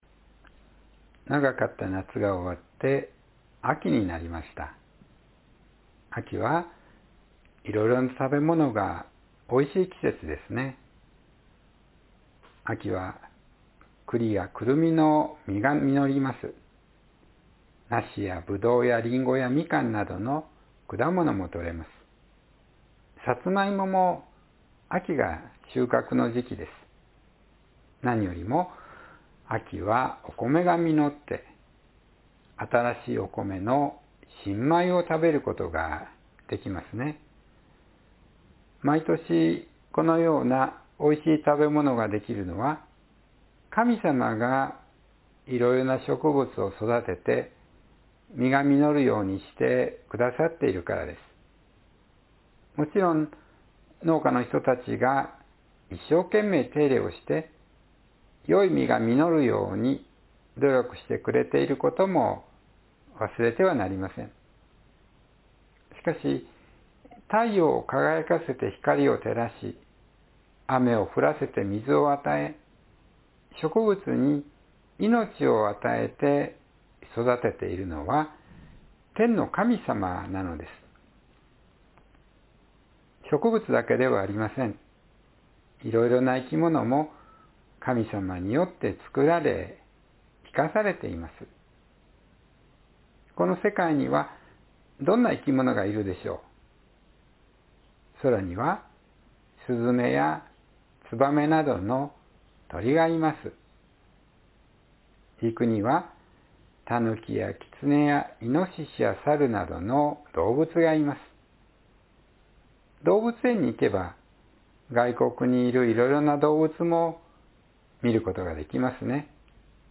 世界と人間を創造した神さま“God Who Ceated the World and Humans”（2024年11月3日・子ども説教） – 日本キリスト教会 志木北教会